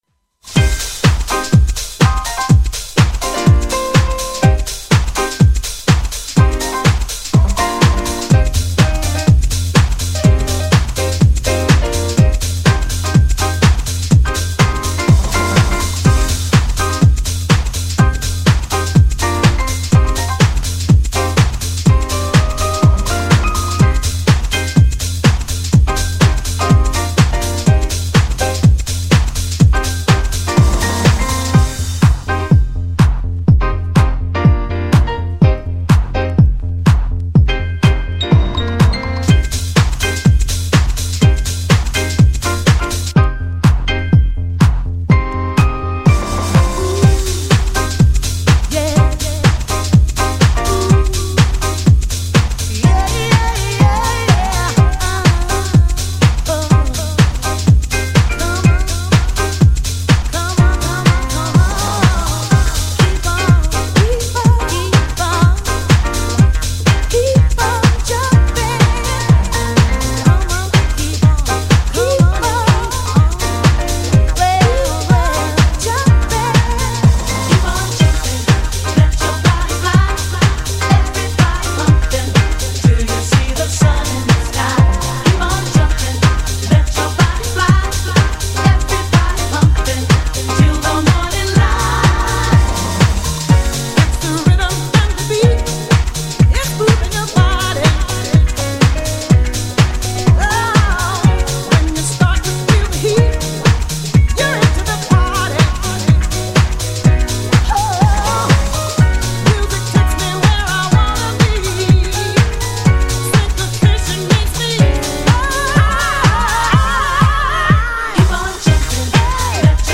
GENRE House
BPM 121〜125BPM